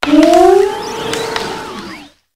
brambleghast_ambient.ogg